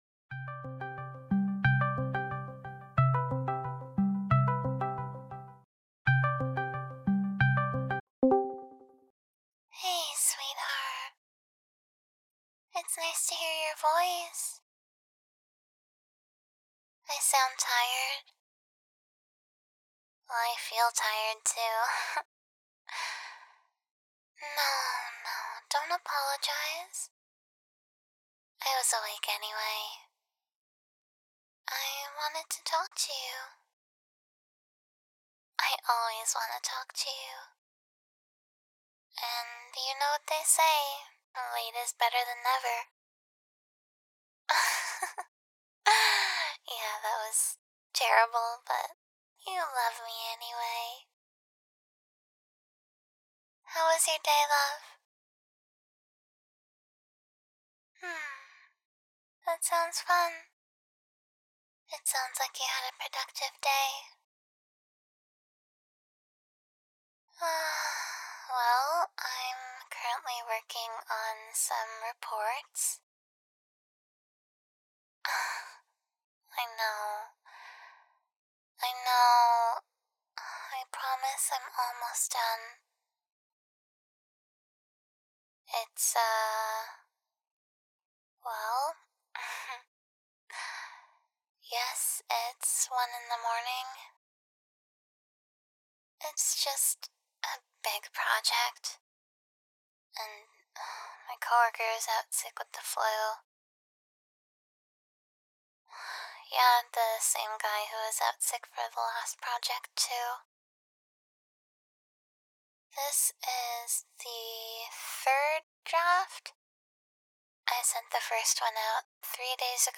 Tags: [SFW] [ASMR] [GFE] [Girlfriend] [Late Night Work] [Attentive GF] [Sleep Aid] [Soft Spoken] [Caring] [Loving] [Family & Holiday Mentions] [Reminiscing] [Established Relationship] [Discord Call] [Yawning] [Sleepy Voice] [Goodnight]
Summary: Your girlfriend is up super late working again, but that doesn't mean you can't chat with her! She's slowly getting sleepier and sleepier as you talk, and when you tell her that you won't go to bed until she does, she finally calls it for the night.